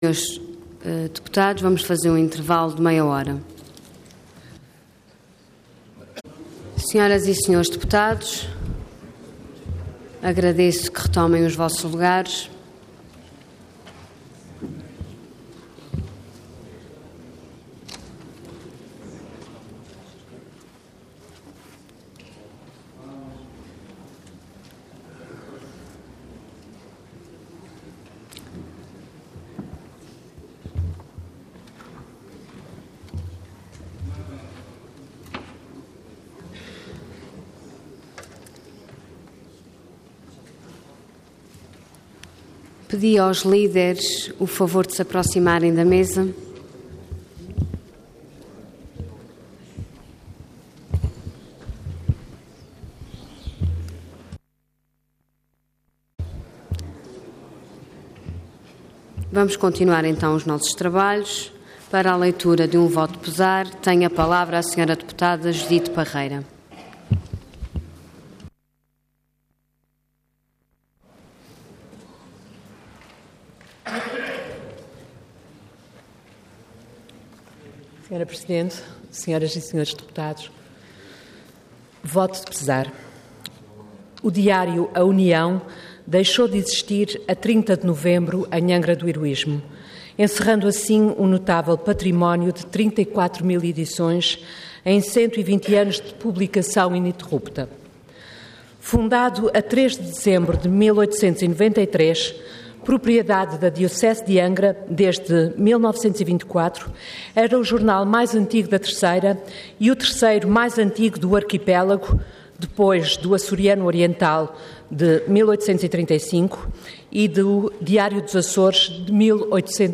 Intervenção Voto de Pesar Orador Judite Parreira Cargo Deputada Entidade PSD